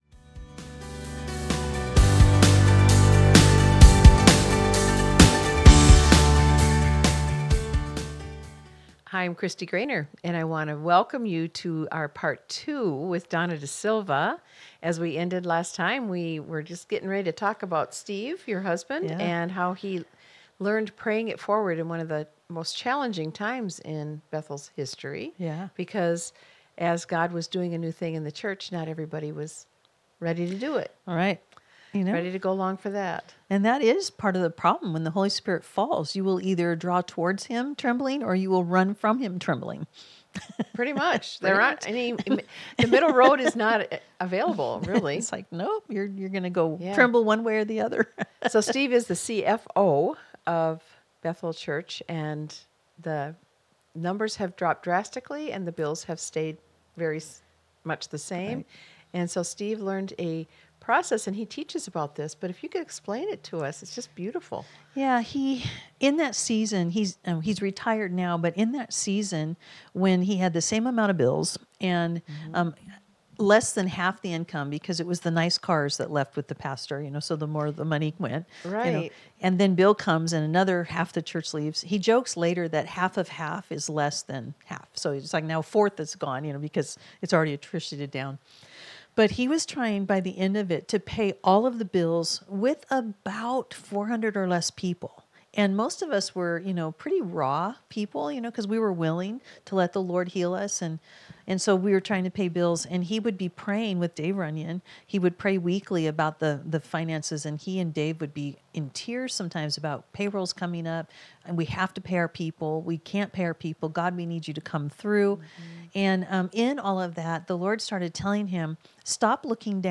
conversation podcast